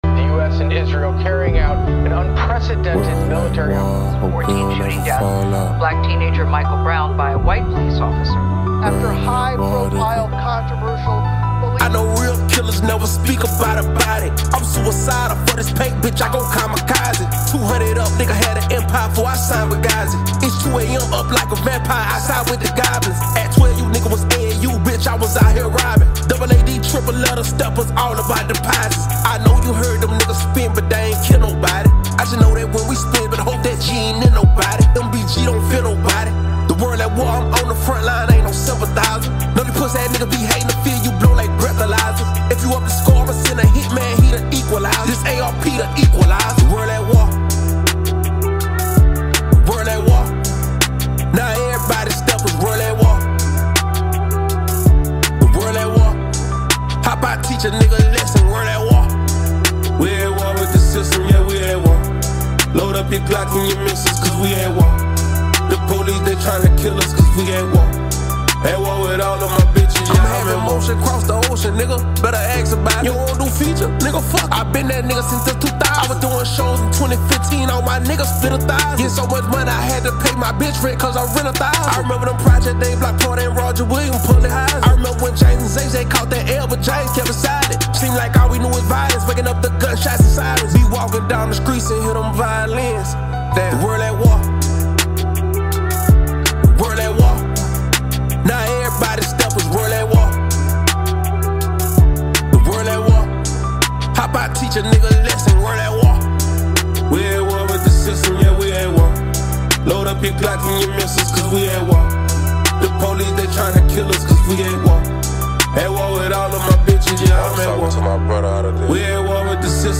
a smooth and engaging tune